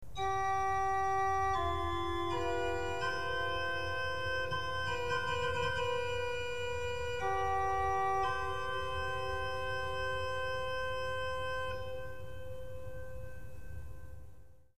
Tremolo, Tremulant
Urządzenie służace do rytmicznego zakłócania ciśnienia powietrza dopływającego do wiatrownicy, w efekcie powodujące falowanie dźwięku.
tremolo_sesquialtera_z_8.mp3